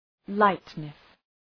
{‘laıtnıs}